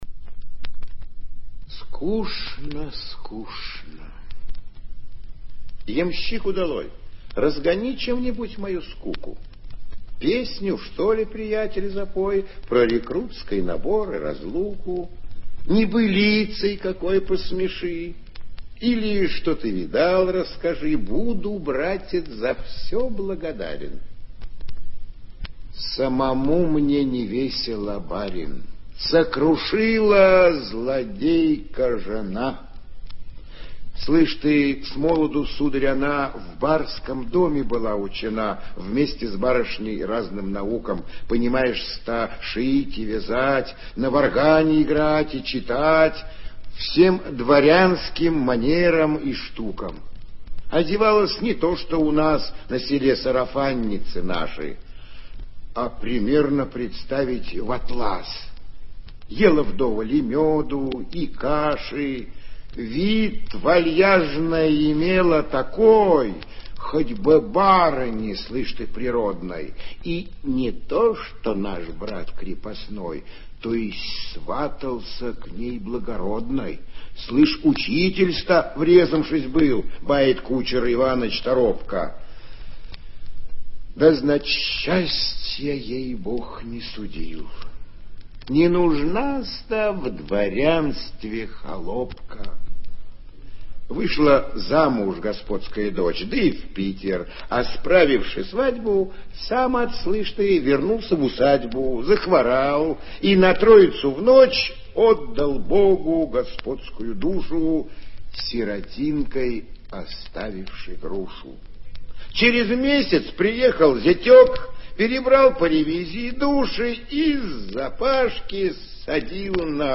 Прослушивание аудиозаписи стихотворения с сайта «Старое радио». Исполнитель А. Грибов.